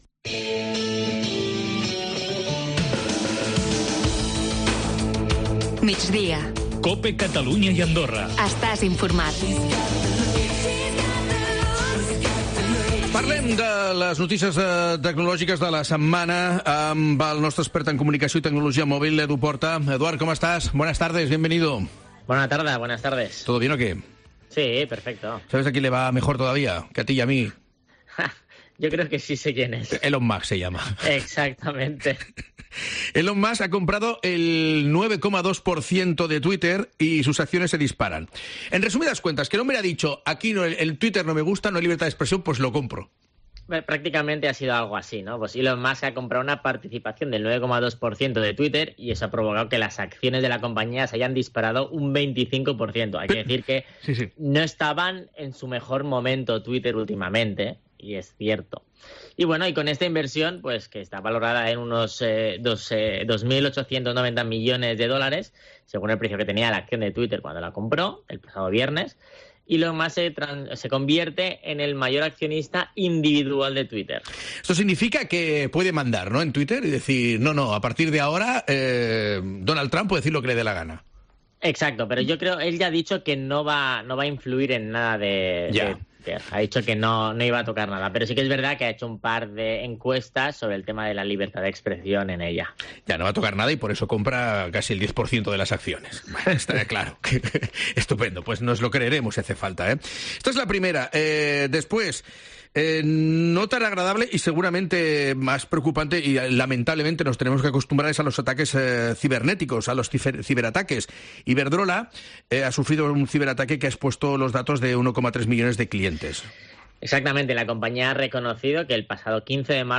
expert en comunicació i tecnologia mòbil, ens explica les novetats tecnològiques de la setmana a Migdia a COPE Catalunya i Andorra.